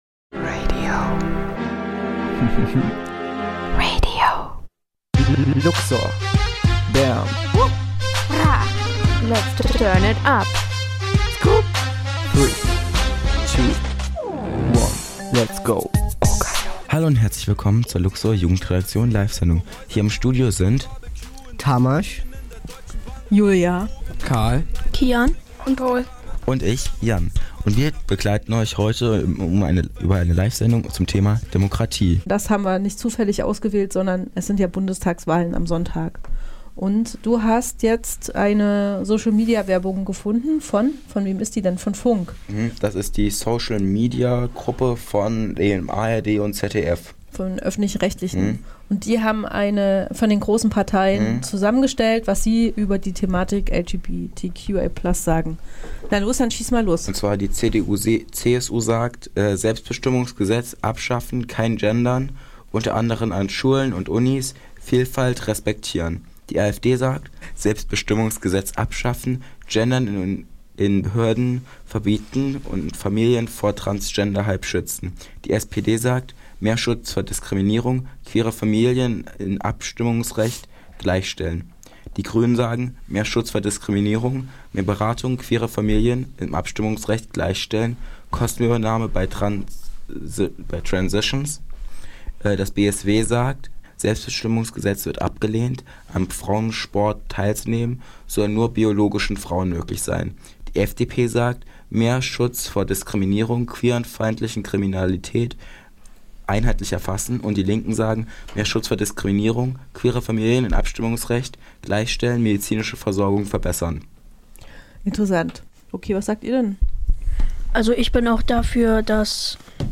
live auf Sendung